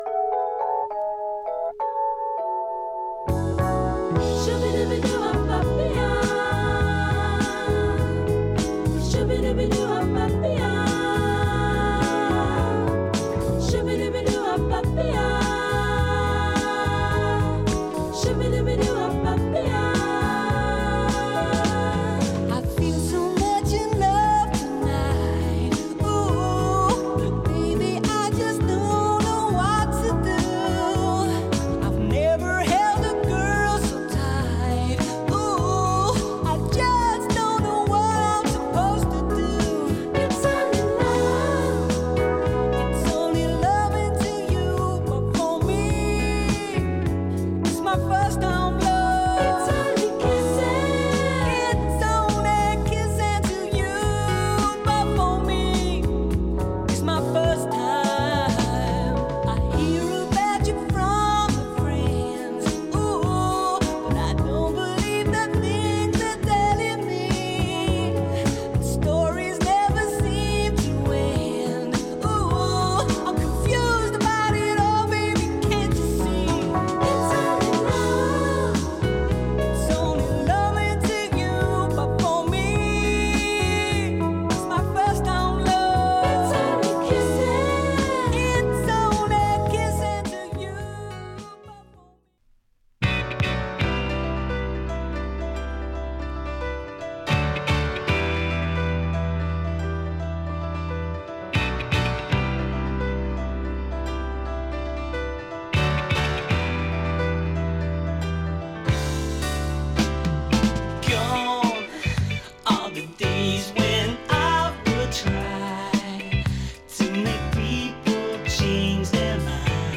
英国の80's ブギーソウル〜AOR〜funkの名作。
ミディアム・グルーヴ